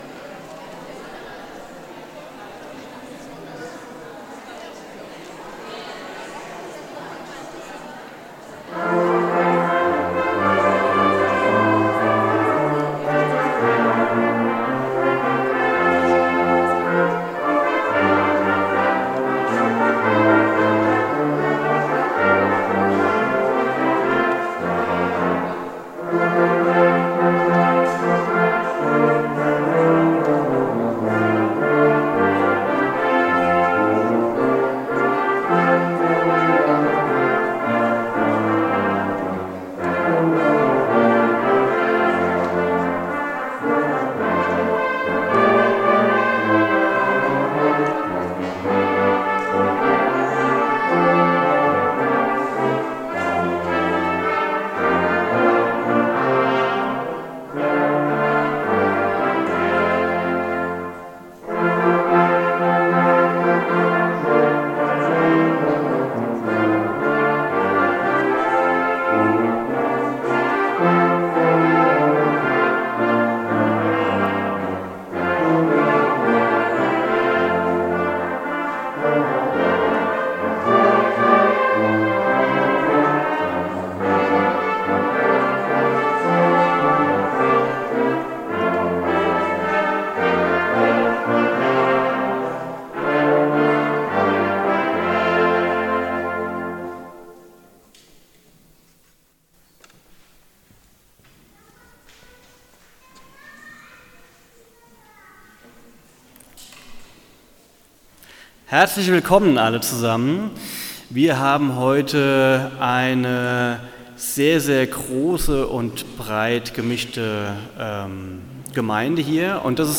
Gottesdienst vom 21.09.2025 als Audio-Podcast Liebe Gemeinde, herzliche Einladung zum Gottesdienst vom CVJM-Jahresfest vom 21. September 2025 in der Martinskirche Nierstein als Audio-Podcast.